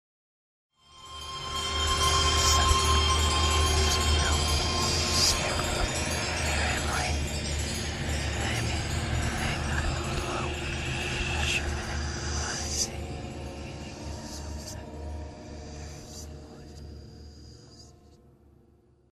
Genere: heavy metal
Rovesciato